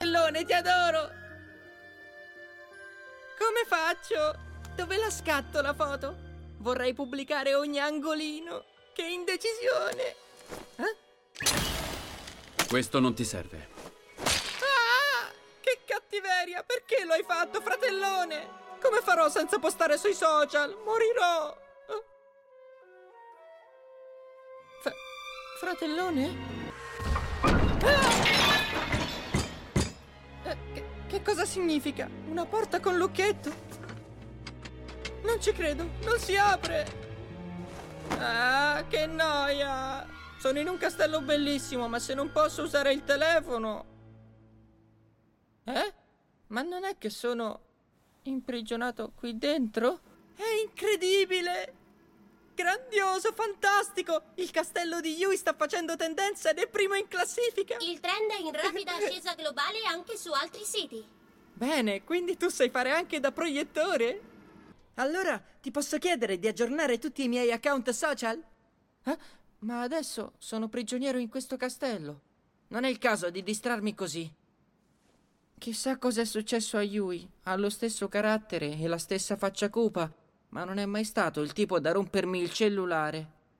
nel cartone animato "Kemono Jihen", in cui doppia Akira.